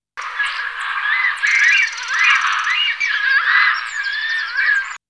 Cigogne noire
Ciconia nigra
cigogne_n.mp3